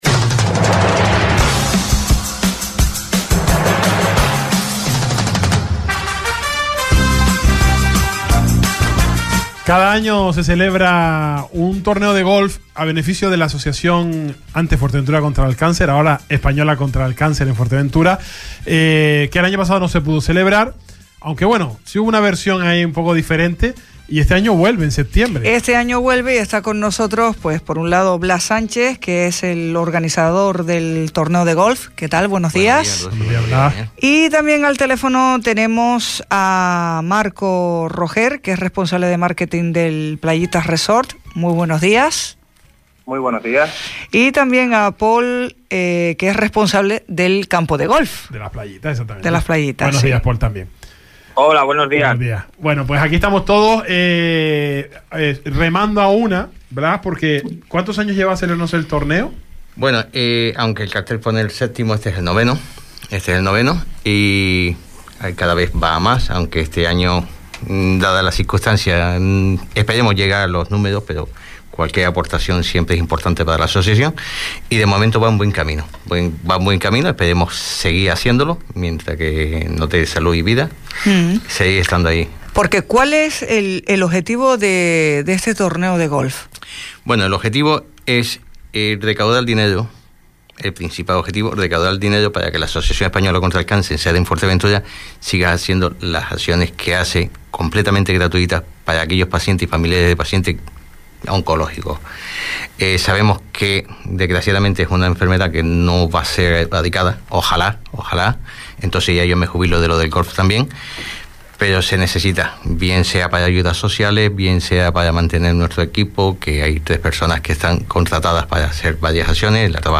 El Salpicón, entrevista